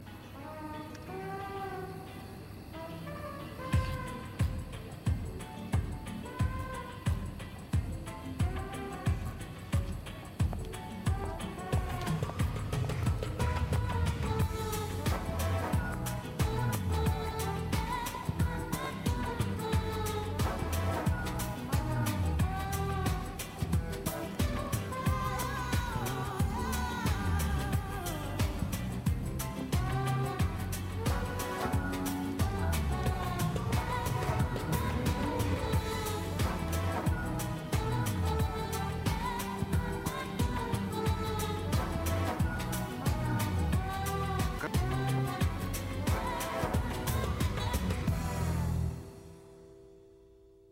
Запись из фильма прилагаю.